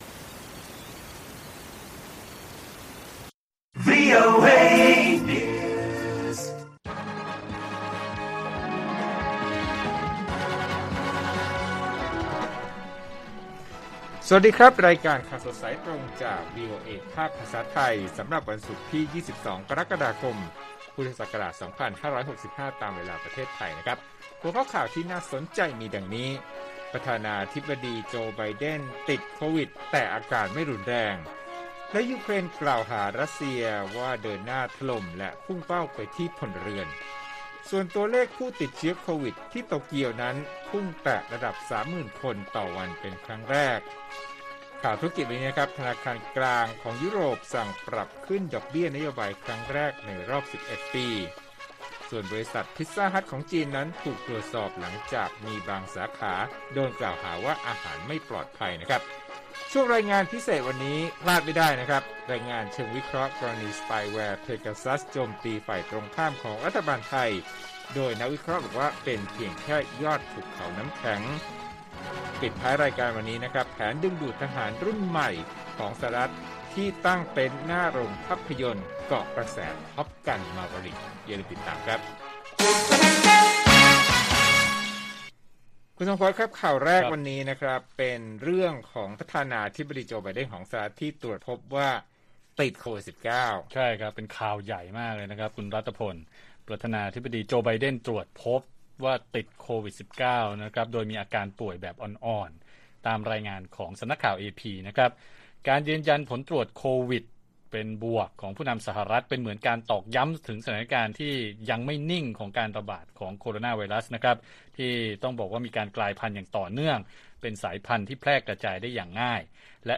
ข่าวสดสายตรงจากวีโอเอไทย 8:30–9:00 น. วันที่ 22 ก.ค. 65